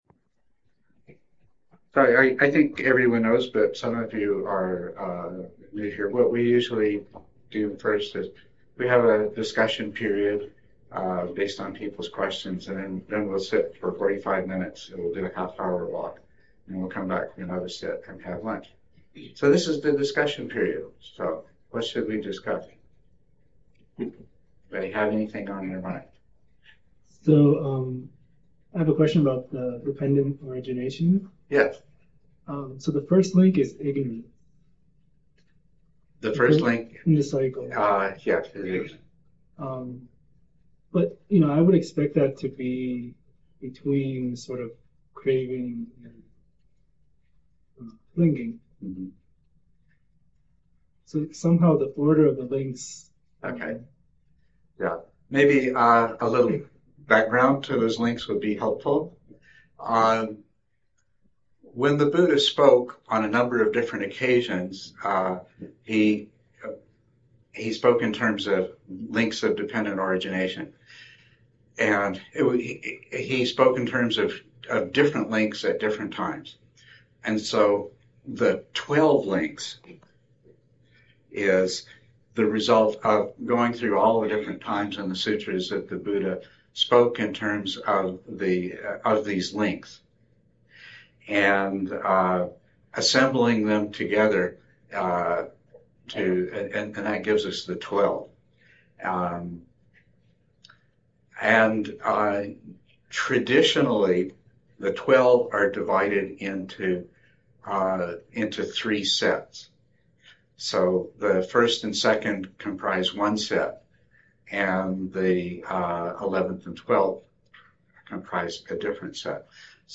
Talks uposatha
Edit talk Download audio (mp3) Download original audio Listen to cleaned audio * Audio files are processed to reduce background noise, and provide (much) better compression.